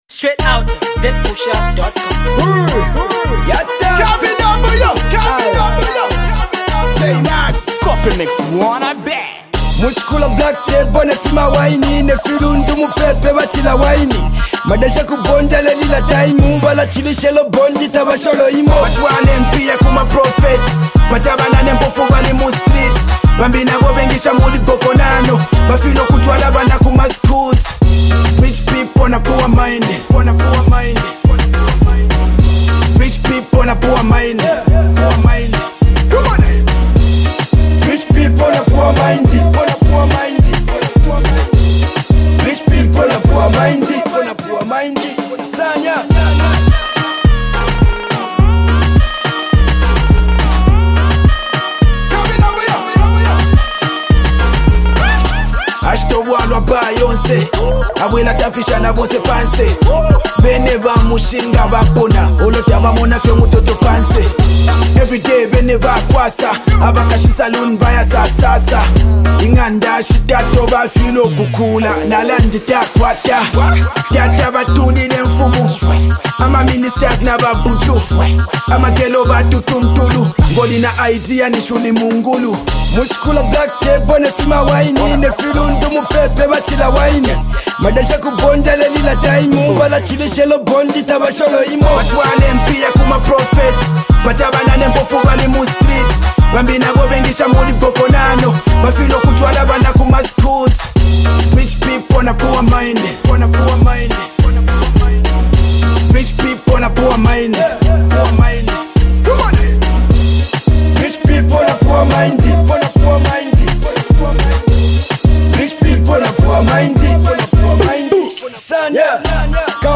Talented stylish rapper